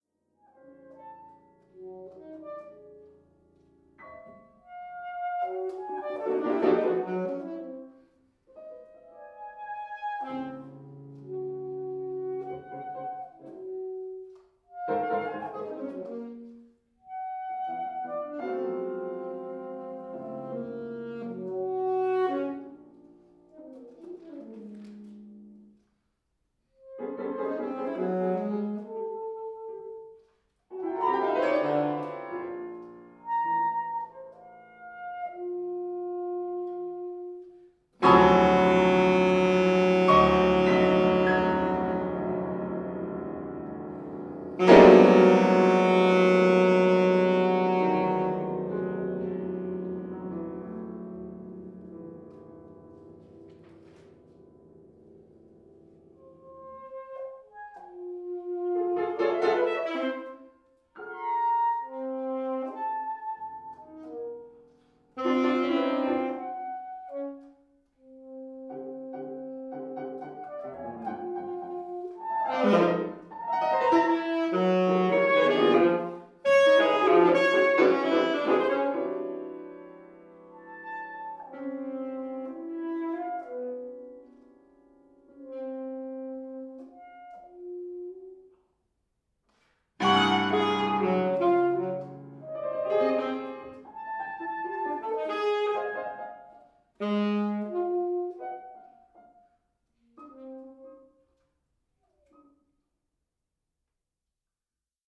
alto saxophone
piano